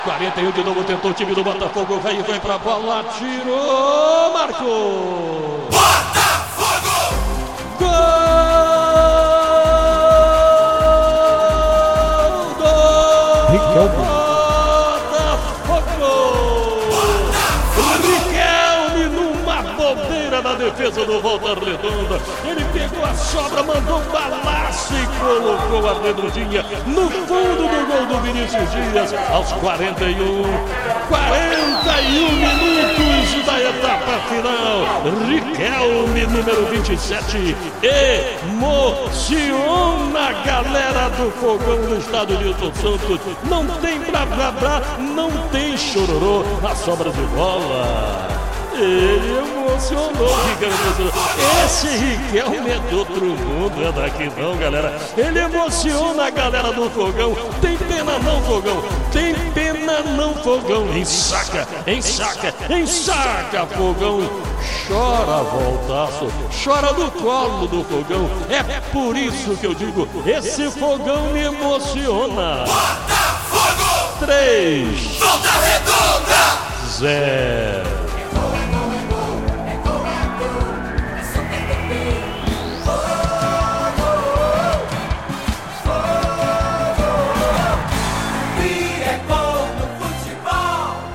narração